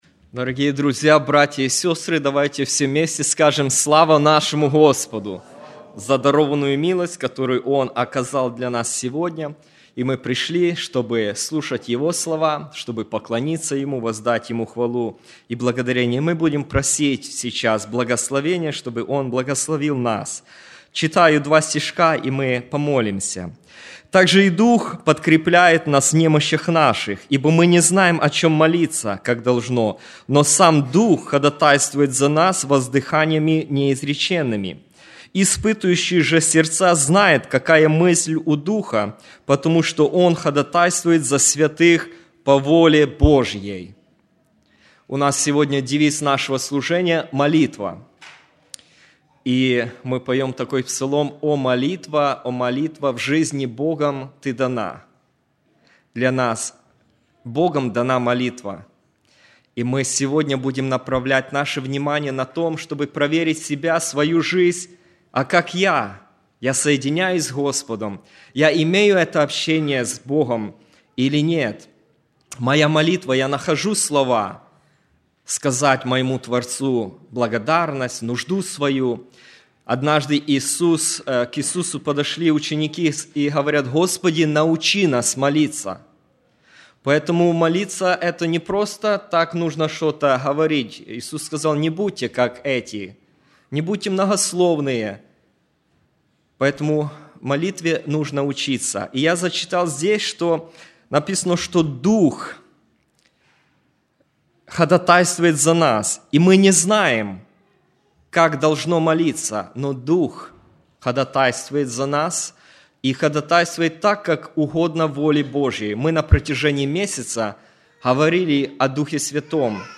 01+Проповедь.mp3